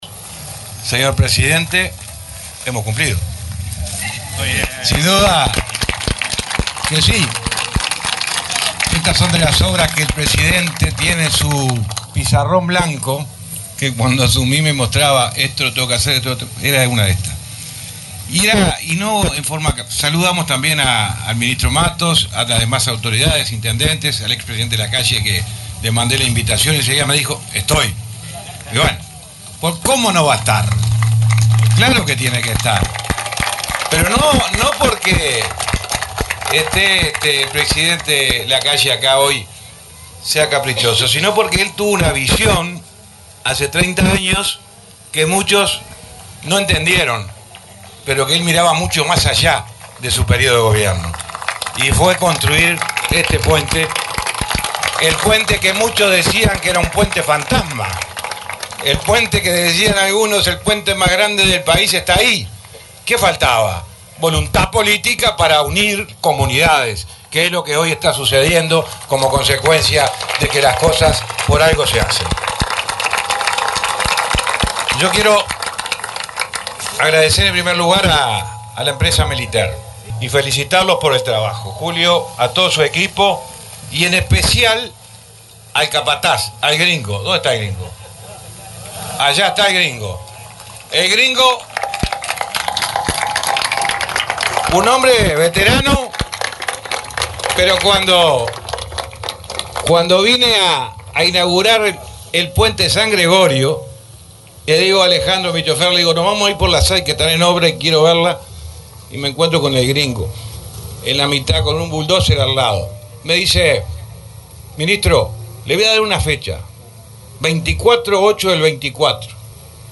Palabras del ministro de Transporte, José Luis Falero
Palabras del ministro de Transporte, José Luis Falero 24/08/2024 Compartir Facebook X Copiar enlace WhatsApp LinkedIn El ministro de Transporte, José Luis Falero, participó, este sábado 24, en la inauguración de la reforma de la ruta n.º 6, que une los departamentos de Durazno y Tacuarembó. El acto se realizó en el kilómetro 329 de la mencionada vía nacional.